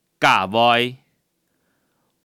Hong/Hakka_tts